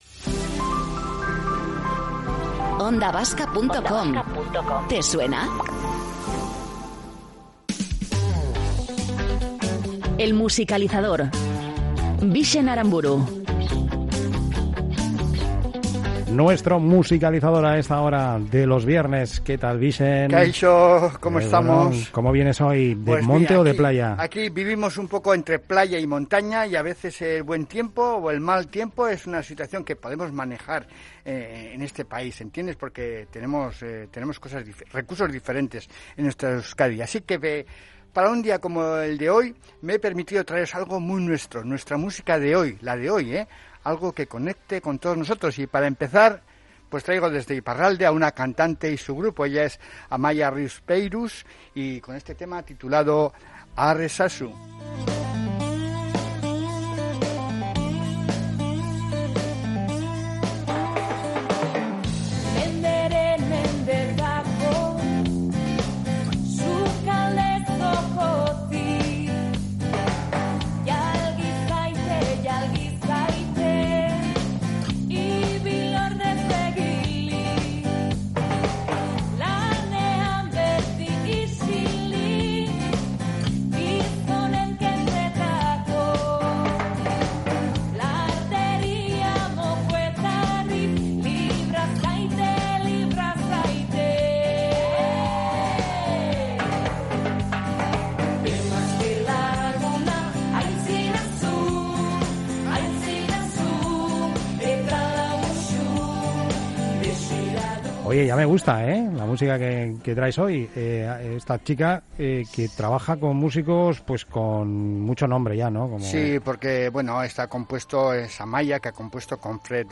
¡A bailar!